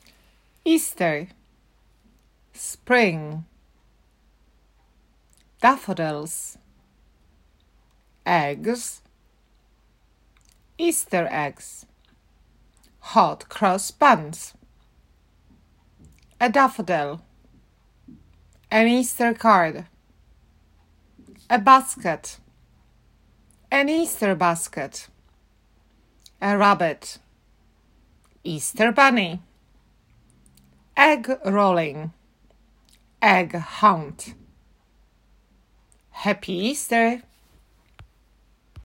easter-vocab_gr1.m4a